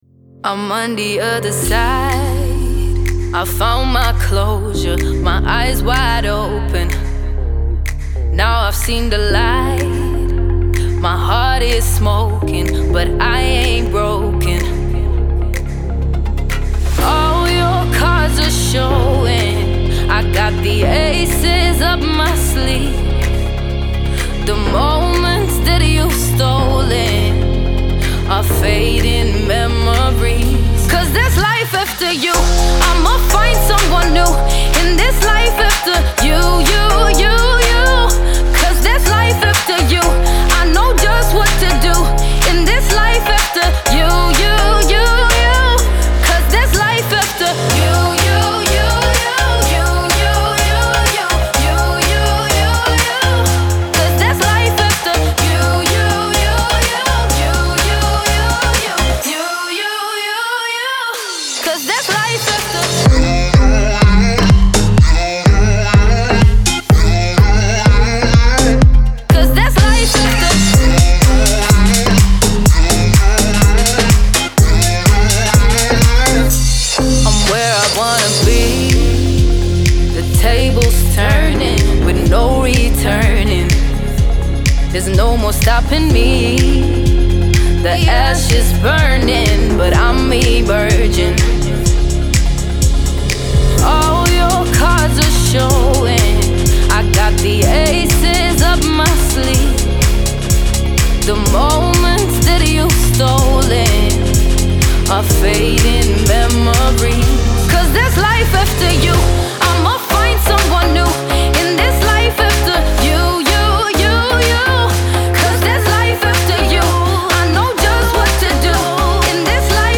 энергичная танцевальная композиция